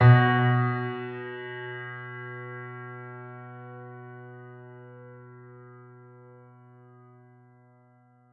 piano-sounds-dev
b1.mp3